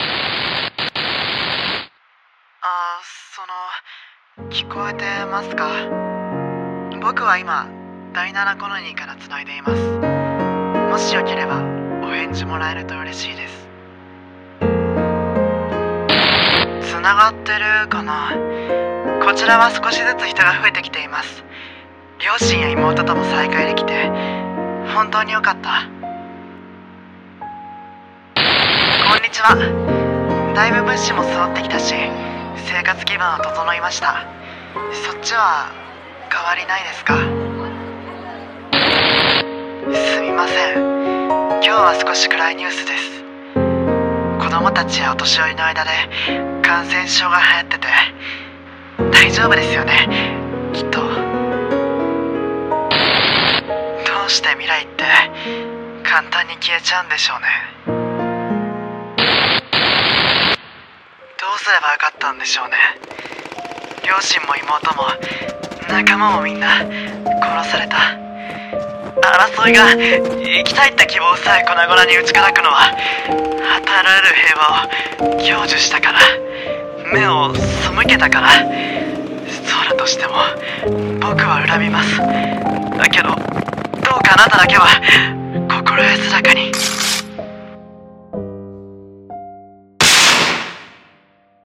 【1人声劇】拝啓、コロニーより